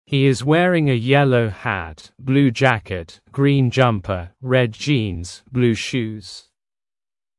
[Хи из уэринг э йеллоу хэт, блю джакет, грин джампер, ред джинз, блю шуз.].